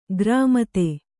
♪ grāmate